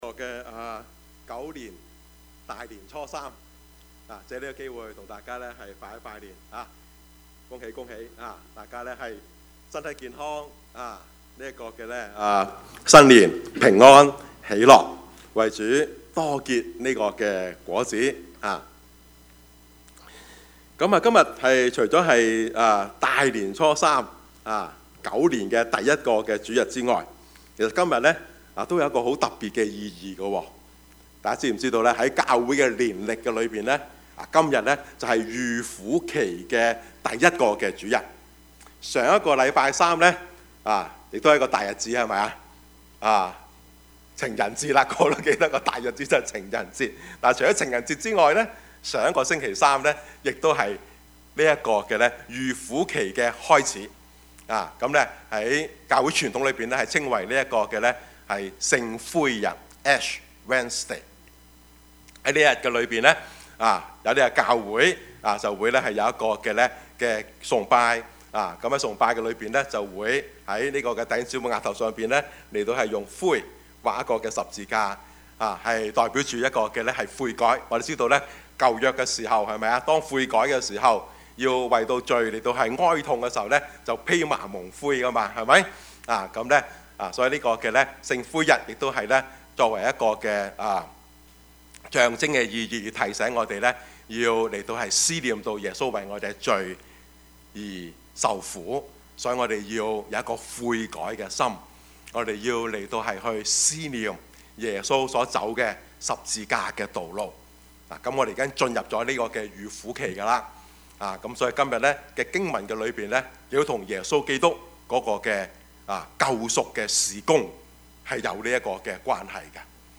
Service Type: 主日崇拜
Topics: 主日證道 « 禱告真義 假的真不了 »